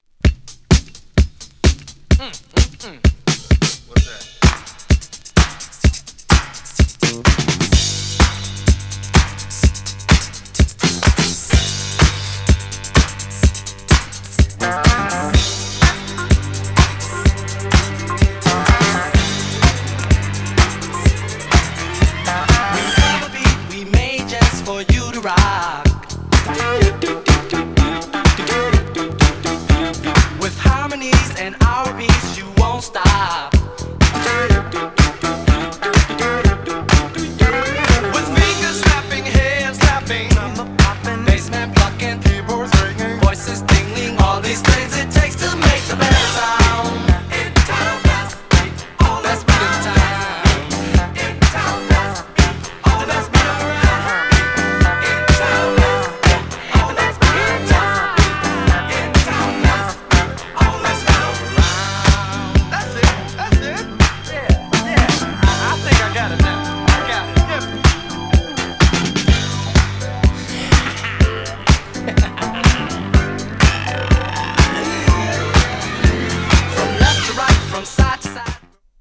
GENRE Dance Classic
BPM 81〜85BPM